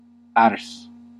Vaihtoehtoiset kirjoitusmuodot (vanhentunut) skil Synonyymit ability talent split ease command competence proficiency expertness skillfulness skilfulness Ääntäminen US : IPA : [ˈskɪɫ] Tuntematon aksentti: IPA : /skɪl/